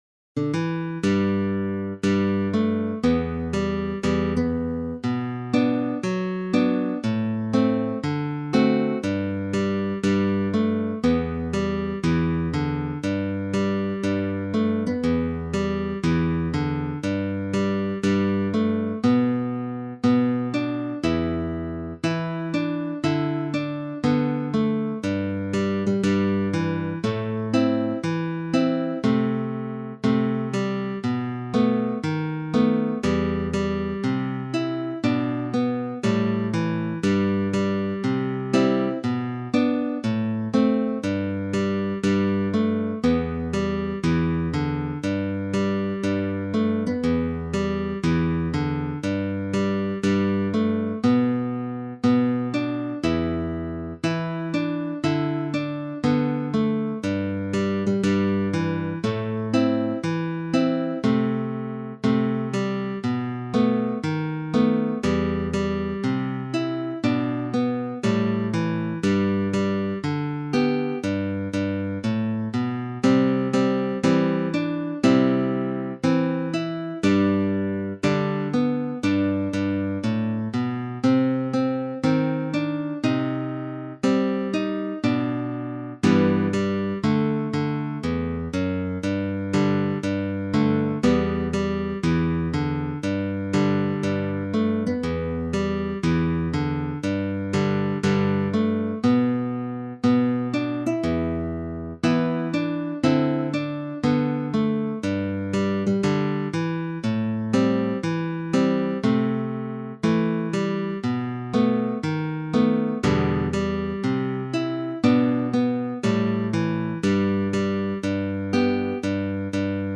歌謡曲・演歌
楽譜の音源（デモ演奏）は下記URLよりご確認いただけます。
（この音源はコンピューターによる演奏ですが、実際に人が演奏することで、さらに表現豊かで魅力的なサウンドになります！）